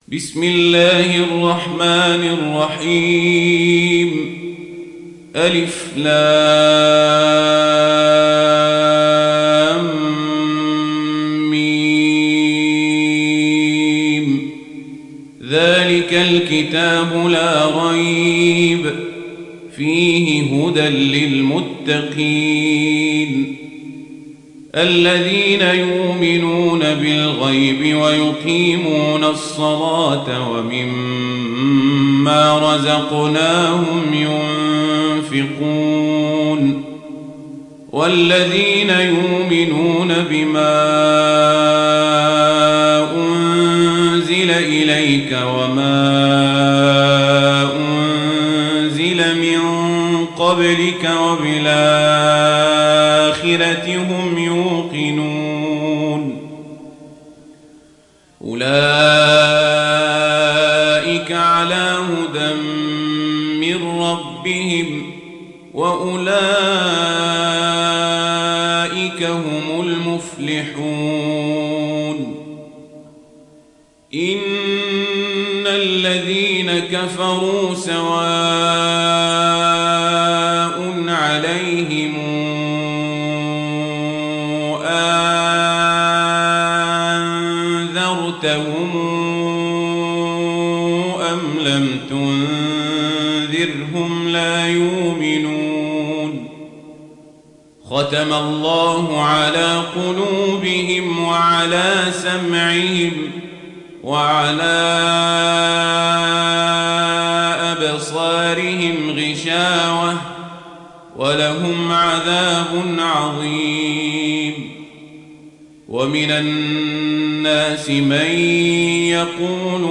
(Riwayat Warch)